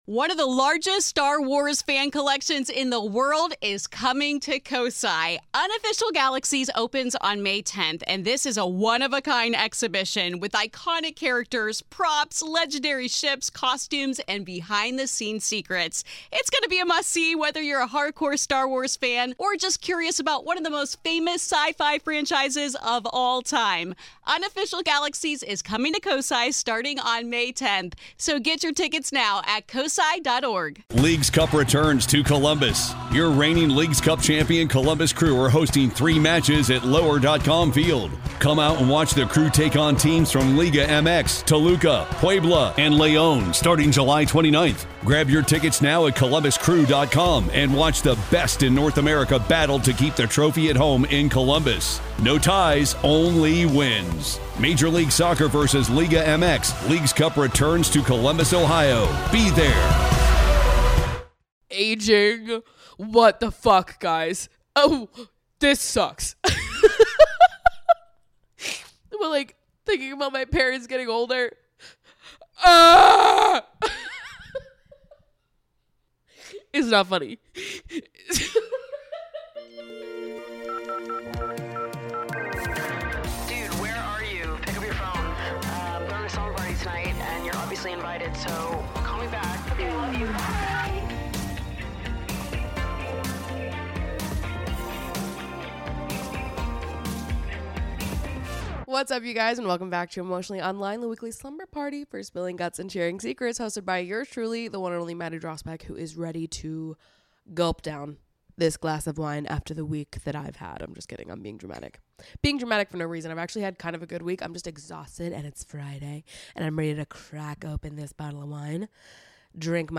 WHY IS IT ALL MOVING SO FAST??? today i'm chatting (crying) about getting older, mourning the eras where family and friends lived closer, and worrying about what the future holds and how we'll all remain intertwined when things get more different. from crying to my therapist, to crying to my dad, to crying for your listening pleasure... this is emotionally online baby!